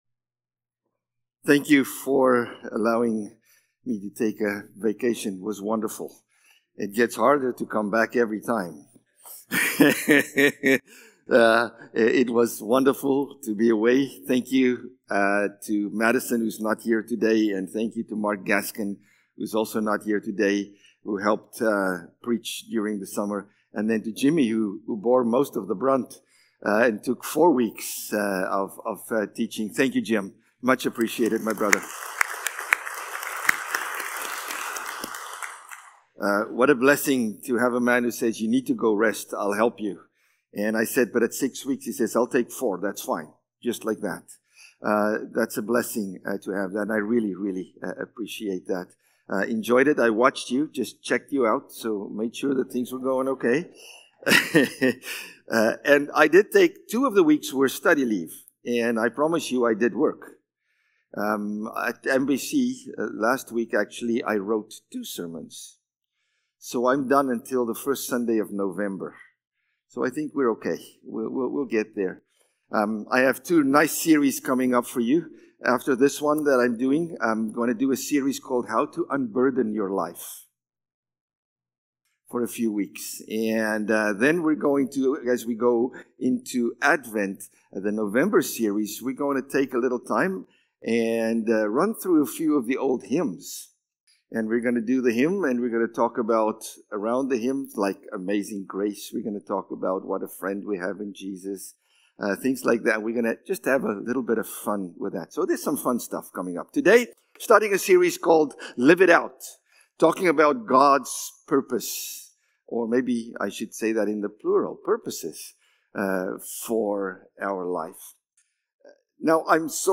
August-18-Sermon.mp3